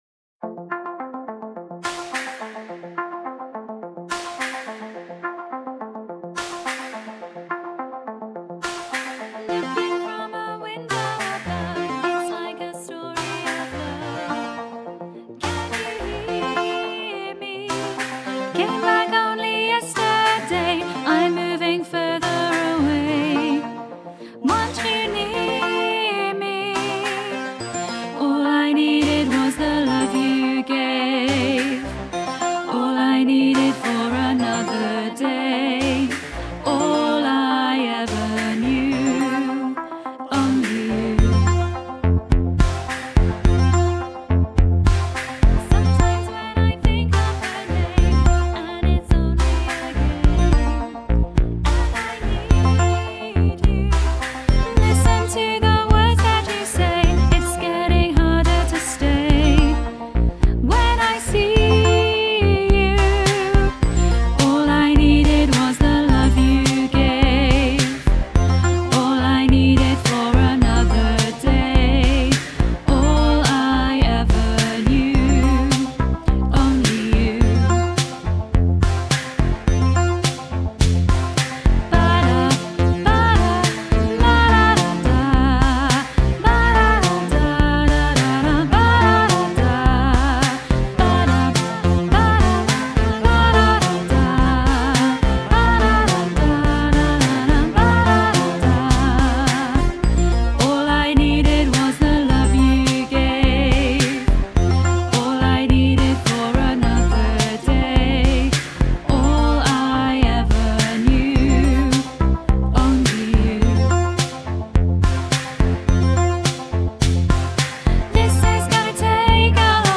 2-only-you-voice-2-high-half-mix.mp3